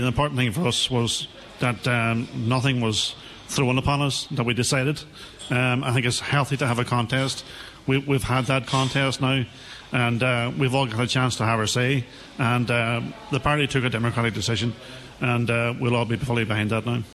Senator Blaney told today’s Nine til Noon Show that despite airing his own views he accepts the democratic process of the party: